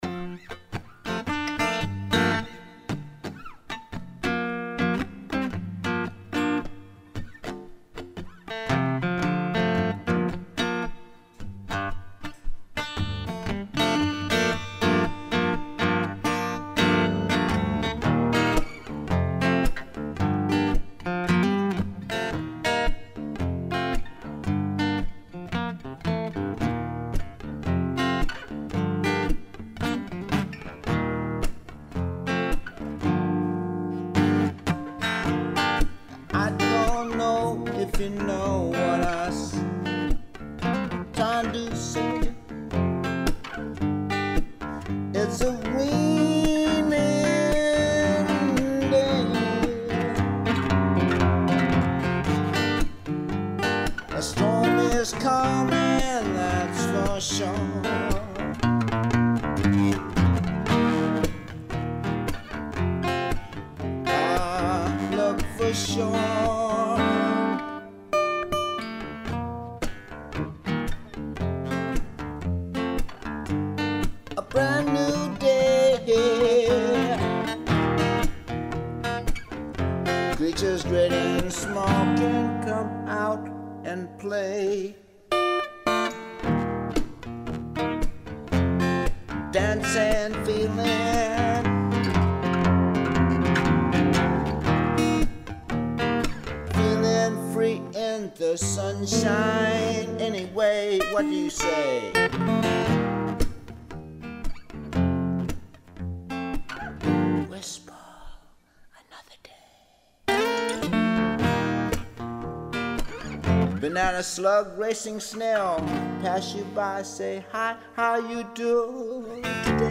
This CD is Guitar and Voice only!